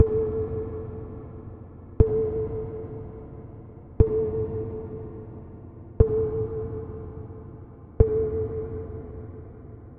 Eerie Dark Ping Sound
Tags: Horror FX Sound